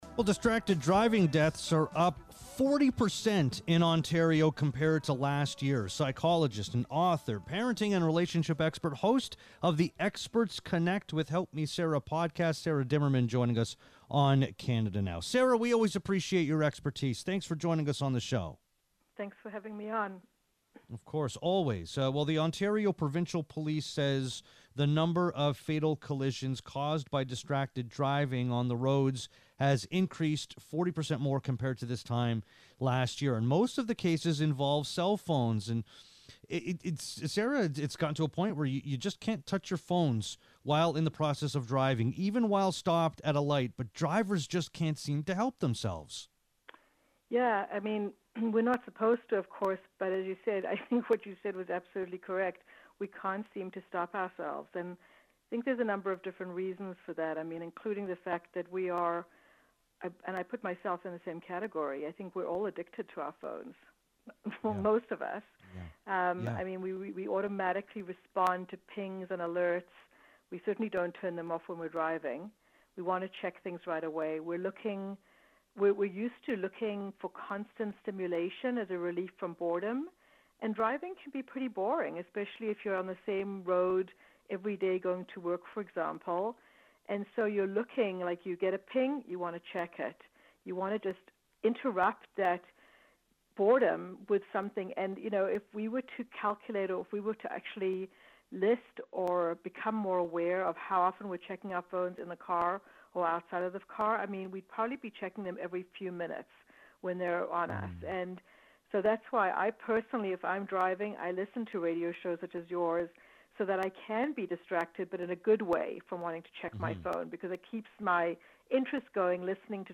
in conversation with occupational therapist